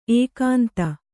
♪ ēkānta